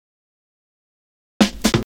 Fill 128 BPM (18).wav